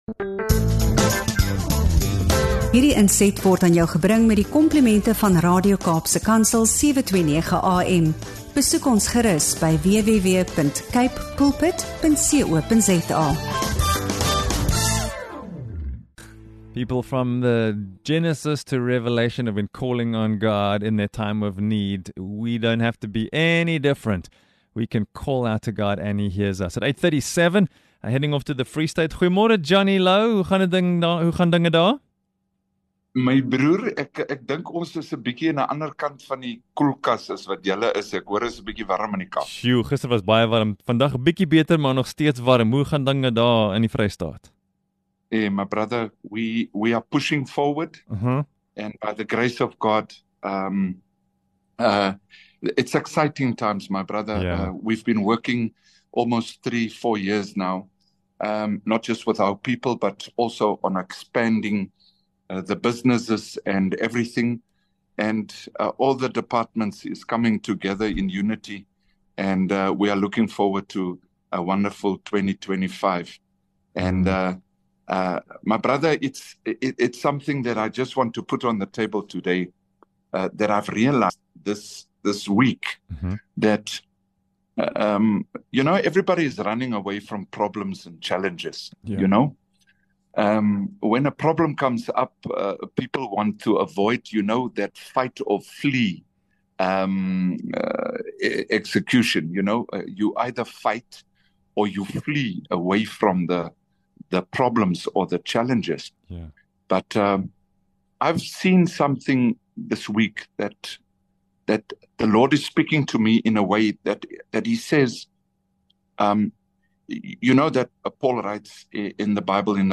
Hy praat vol passie oor sy doel in die lewe—nie net om die liggaam te voed nie, maar om siele met die Evangelie te voed.